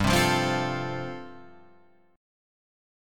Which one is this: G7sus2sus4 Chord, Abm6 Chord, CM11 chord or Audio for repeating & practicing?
G7sus2sus4 Chord